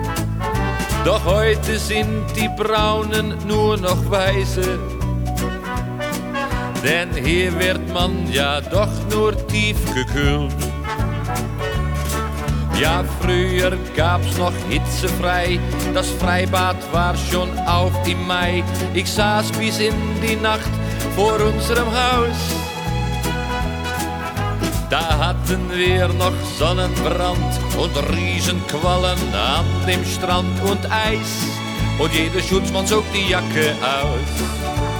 German Pop
Жанр: Поп музыка